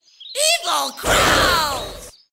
funny movie dialouge